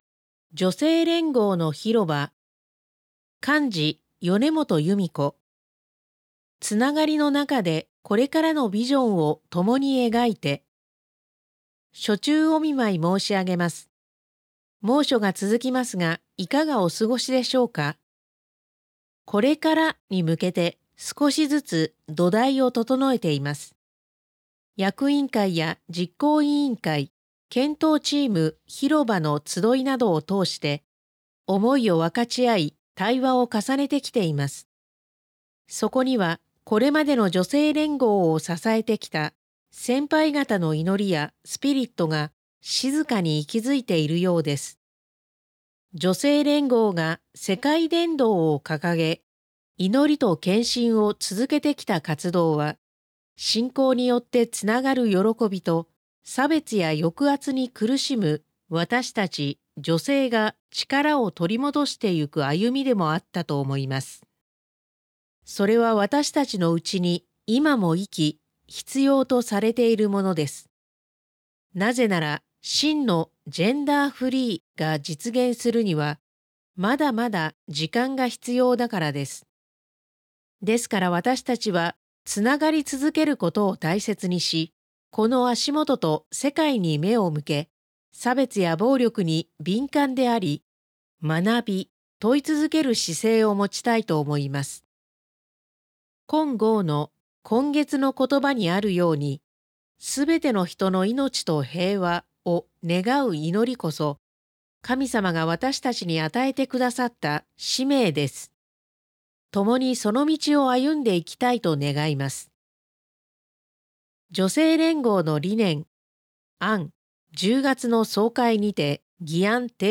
2025年４月号から『世の光』Webの各記事から、朗読者による音声（オーディオ）配信がスタートしました。